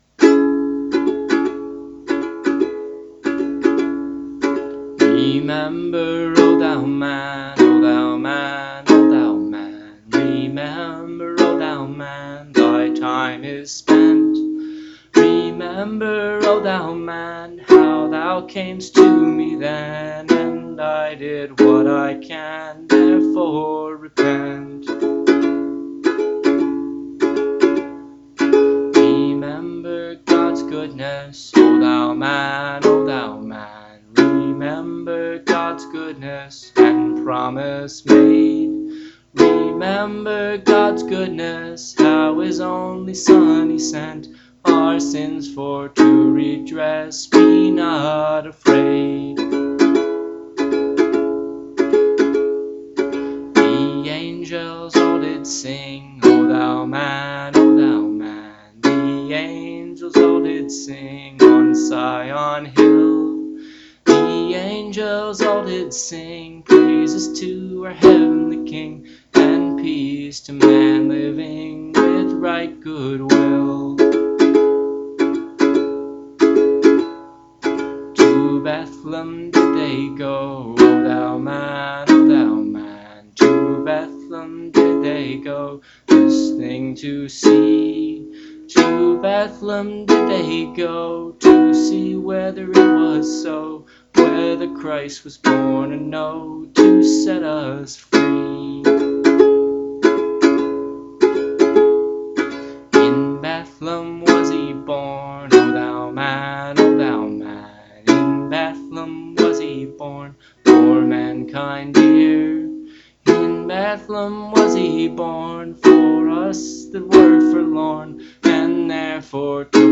ukulele and voice